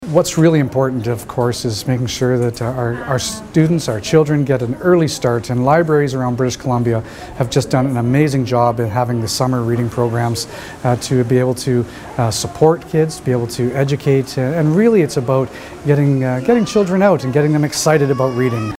Education Minister Mike Bernier says the program is celebrating its 25th anniversary this summer….